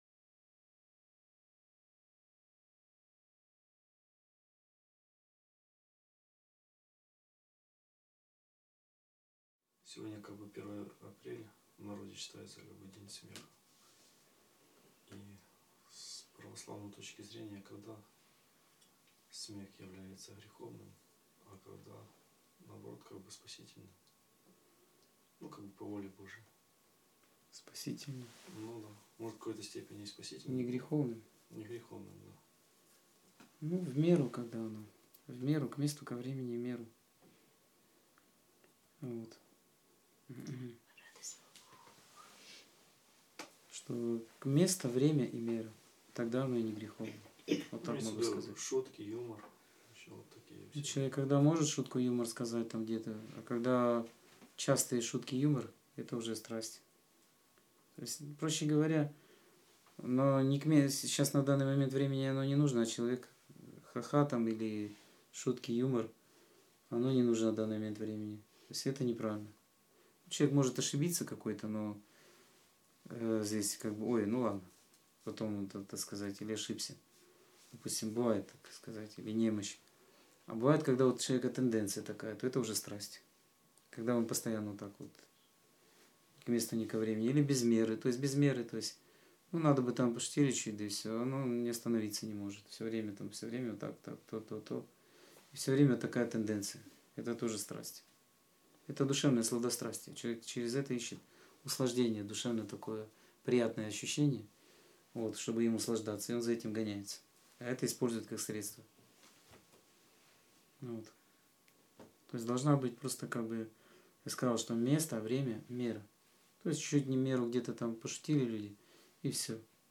Скайп-беседа 25.05.2013